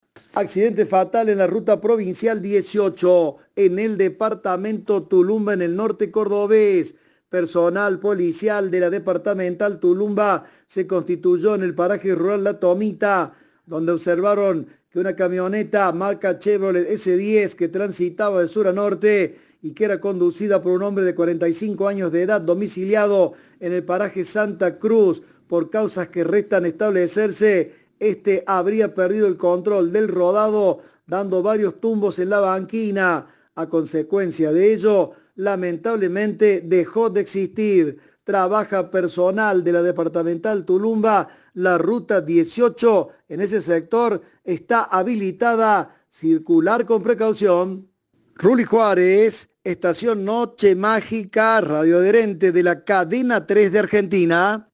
Informes